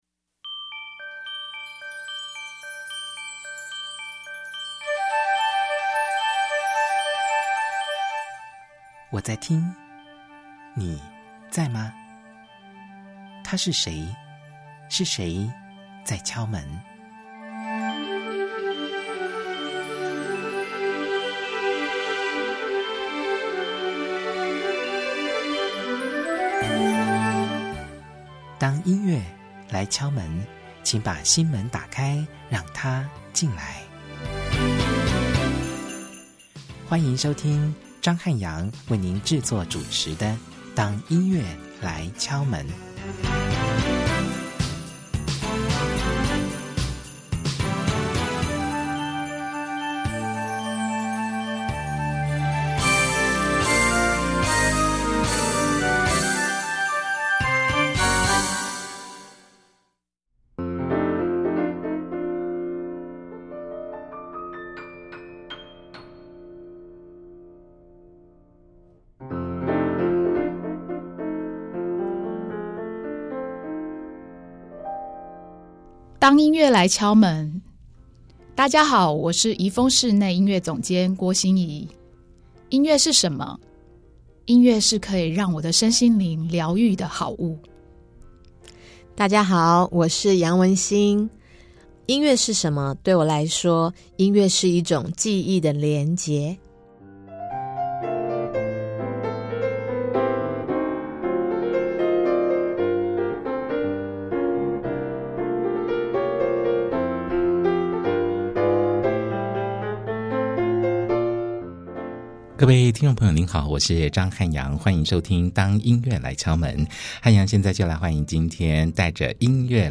歡迎收聽本集節目，一起在笑聲與哽咽聲中，傾聽「來自風?城的聲音」。